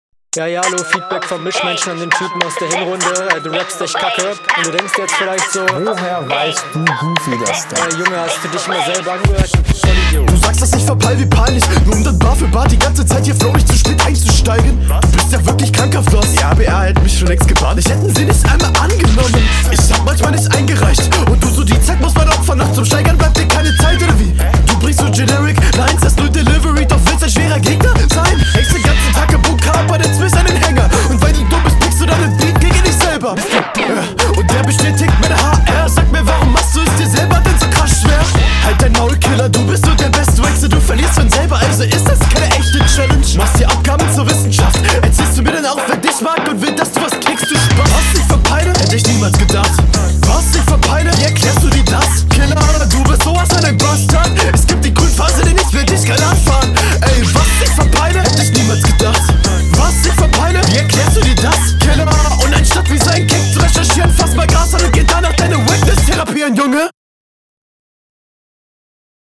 s-laute machen ganz dolle aua in meinen ohren. außerdem gehst du im beat unter und …
Kommst besser auf dem Beat, gute Konter, Hook auch gut gekontert, dope Runde.